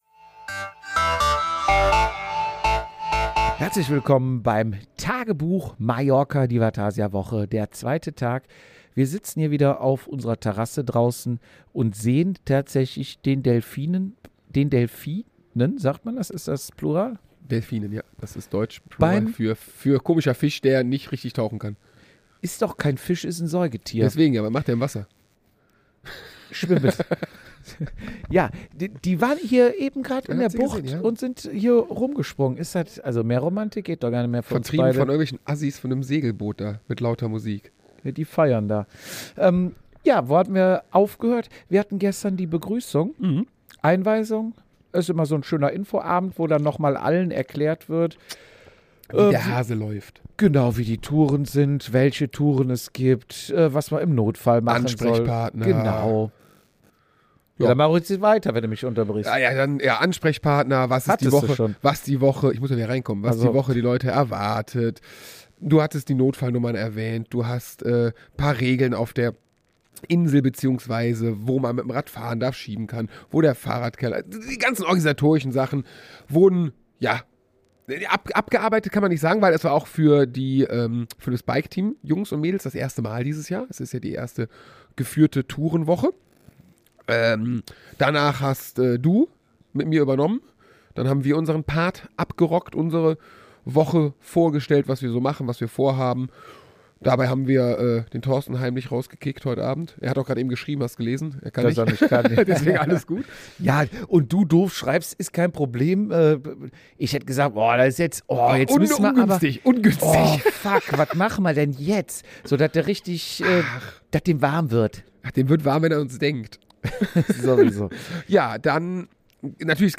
Wir berichten direkt von der Terrasse, während Delfine in der Bucht